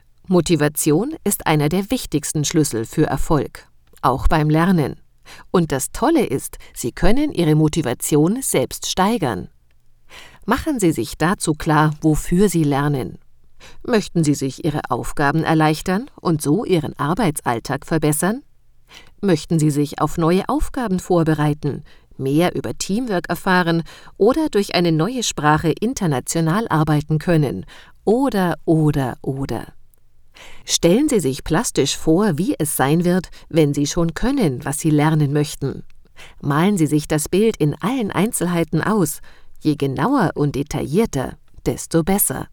deutsche Sprecherin. Warme und freundliche Stimme, die bei Bedarf schneidend, zickig, erotisch oder sarkastisch wird
Sprechprobe: eLearning (Muttersprache):